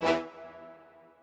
strings10_6.ogg